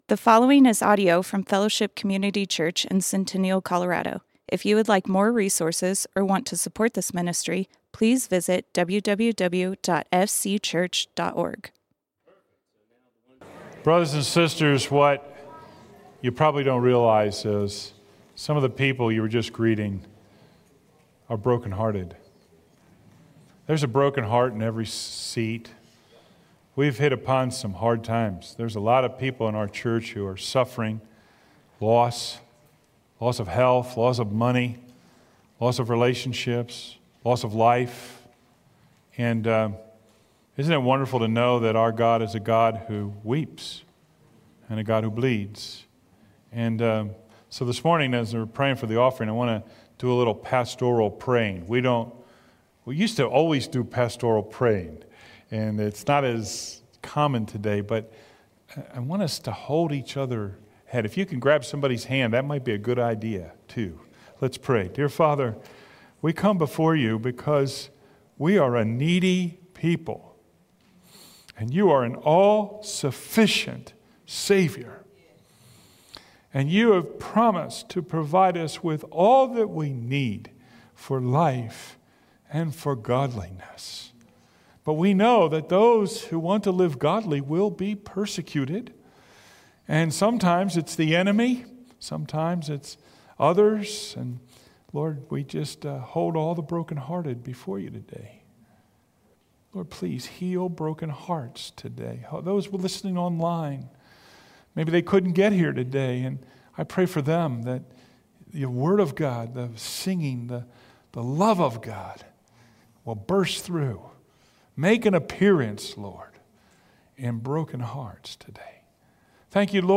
Fellowship Community Church - Sermons If You WERE There Play Episode Pause Episode Mute/Unmute Episode Rewind 10 Seconds 1x Fast Forward 30 seconds 00:00 / 33:47 Subscribe Share RSS Feed Share Link Embed